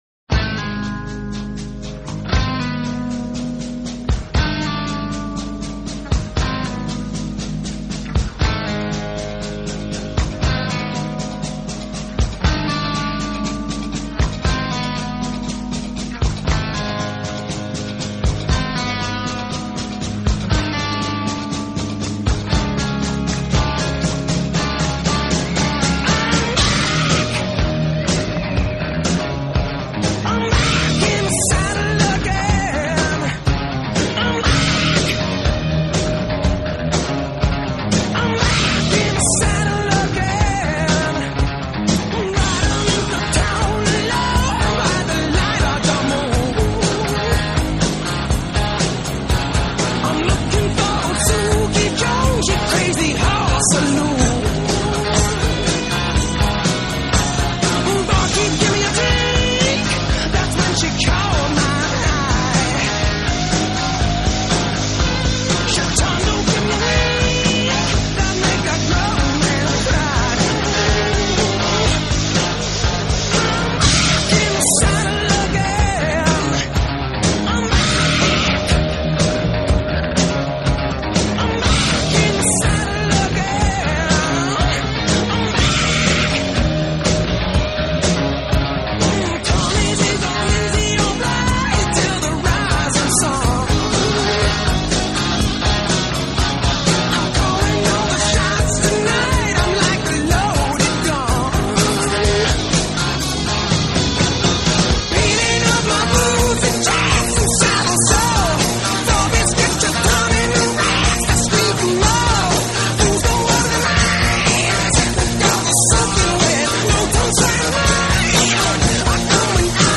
Hard Rock, Blues Rock